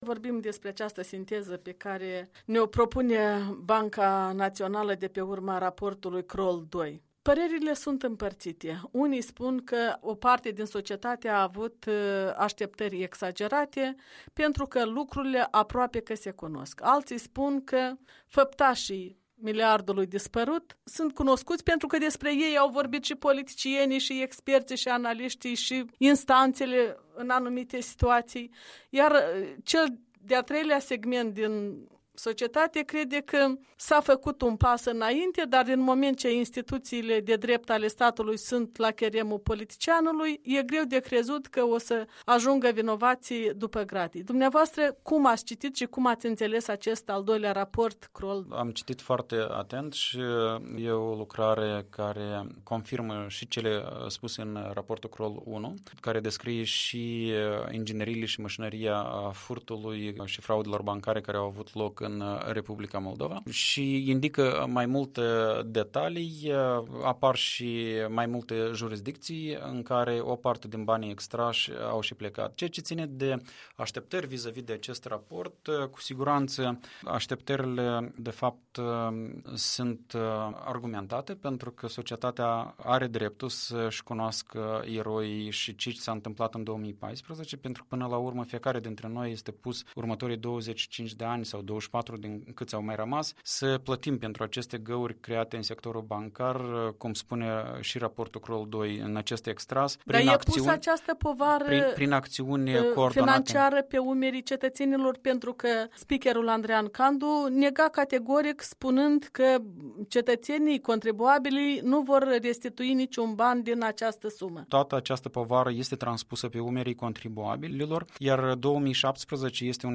Un interviu cu fostul ministru al Finanţelor, în prezent expert al Transparency International-Moldova.
Un interviu cu Veaceslav Negruţa